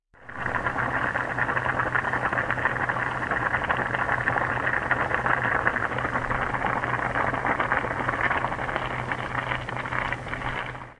沸腾的泡沫 " 沸腾的泡沫2
描述：一个小的记录，里面有一个煮着意大利面条的锅。
Tag: 酿造 气泡